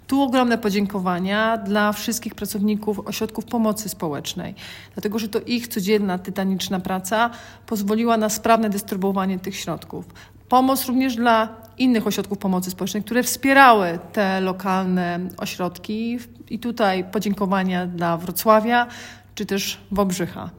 Wojewoda dziękuje wszystkim, którzy przyczynili się do sprawnego dystrybuowania środków.